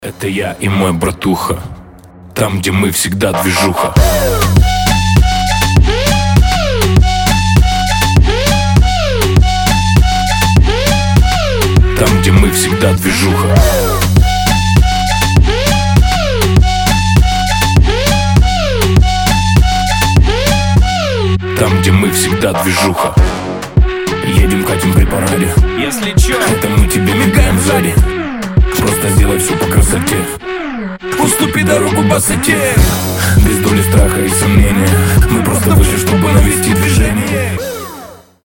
• Качество: 320, Stereo
Moombahton
Сирена